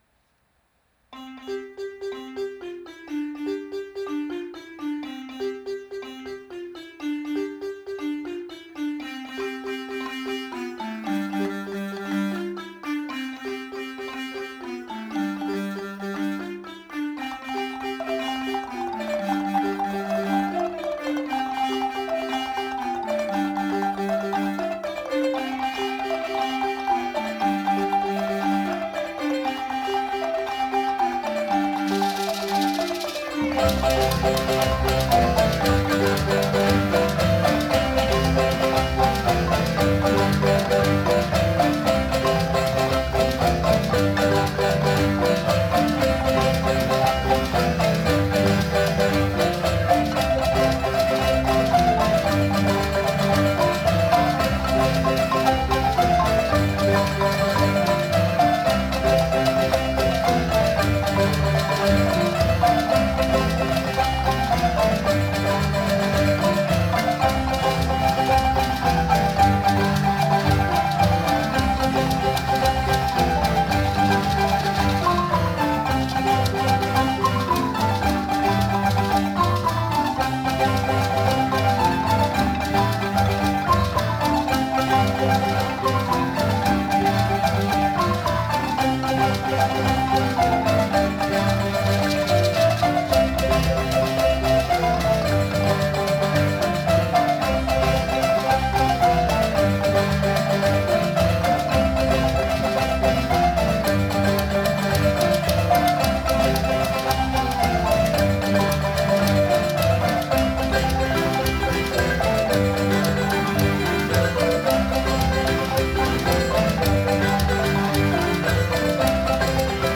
Please enjoy these selections of music presented by the Sellwood Bands.